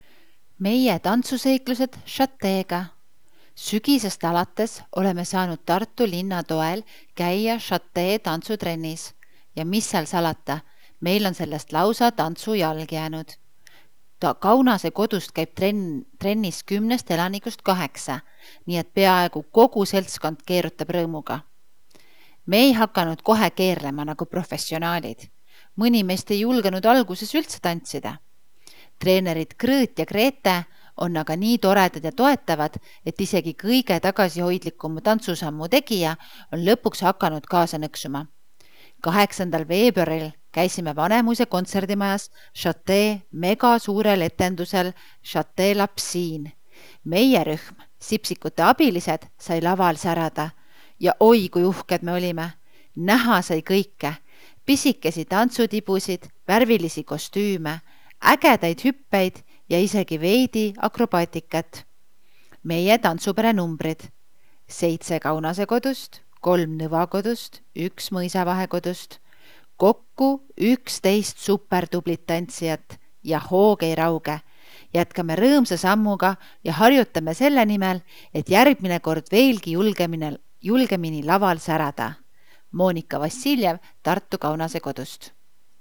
Sipsikute tantsurühm laval.